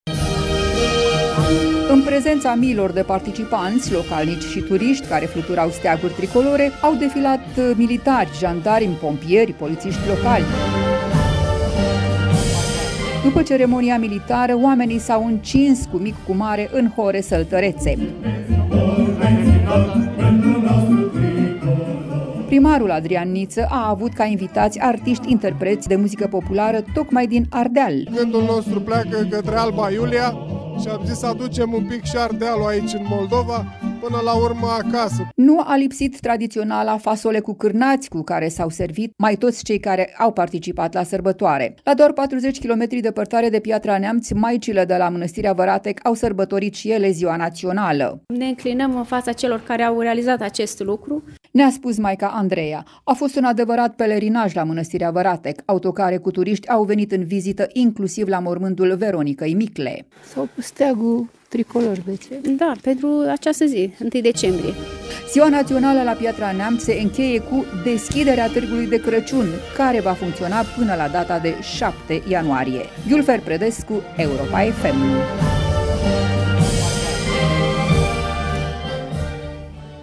În prezența miilor de participanți, localnici și turiști care fluturau steaguri tricolore, au defilat militari, jandarmi, pompieri și polițiști locali.
După ceremonia militară, oamenii s-au încins, cu mic, cu mare, în hore săltărețe.
Primarul Adrian Niță a avut ca invitați artiști interpreți de muzică populară, tocmai din Ardeal: „Gândul nostru pleacă către Alba Iulia și am zis să aducem un pic și Ardealul aici, în Moldova, până la urmă, acasă.”